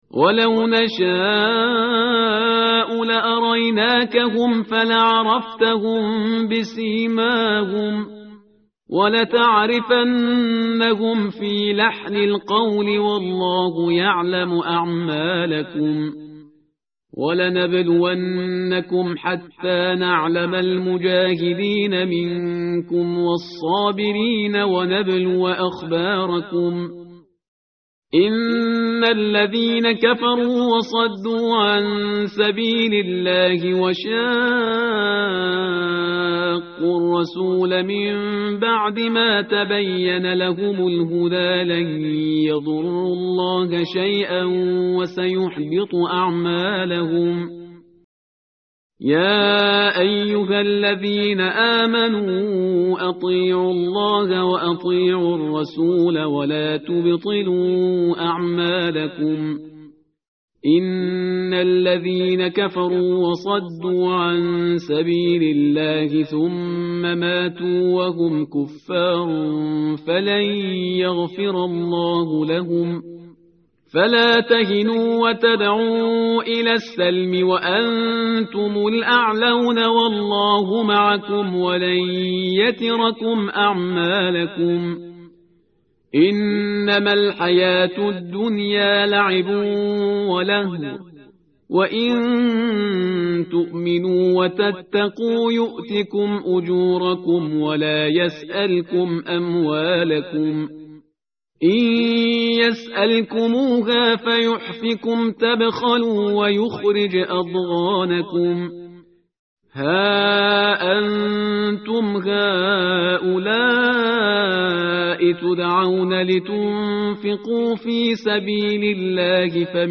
tartil_parhizgar_page_510.mp3